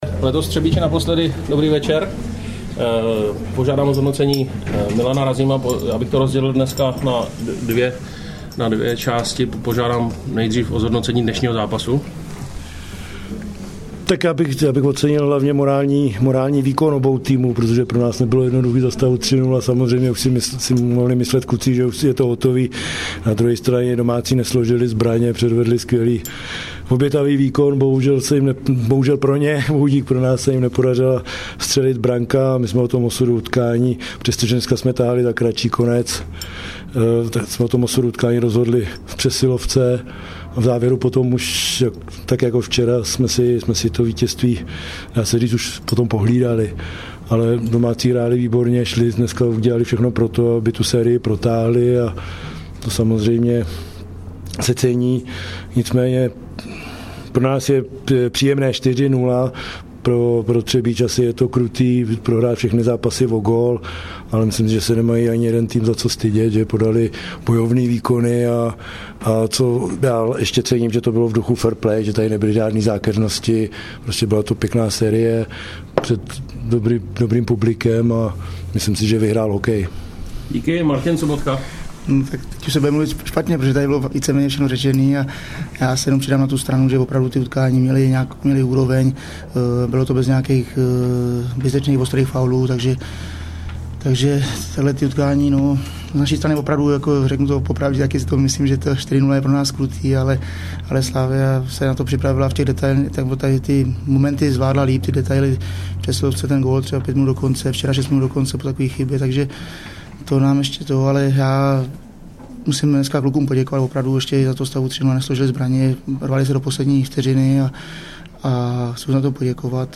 Tiskovka po CF4: Třebíč - Slavia 0:1 1. část
Ohlasy trenérů
Tiskovka_po_CF4_Tre_221.mp3